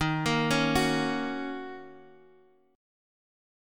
D#m7 Chord